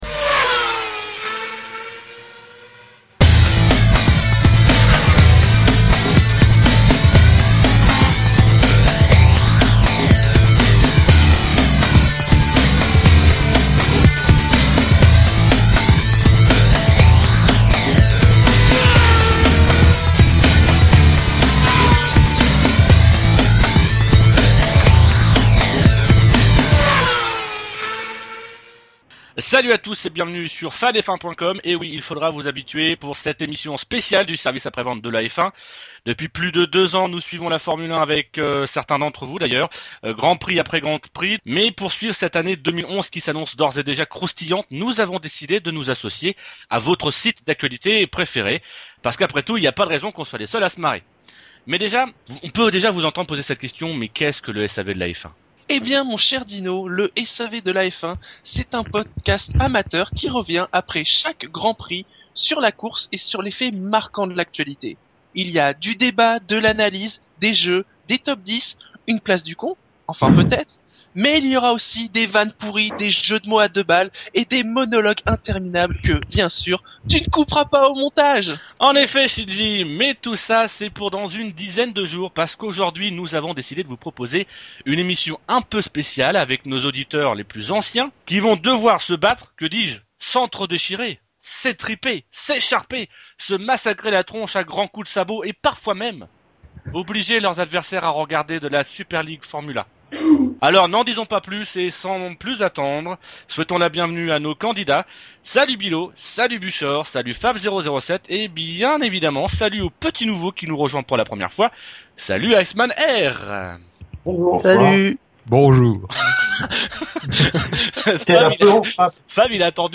À l’approche de cette saison 2011 de Formule Un, le SAV de la F1 reprend du service avec une émission spéciale jeu en compagnie de 4 de ses fidèles auditeurs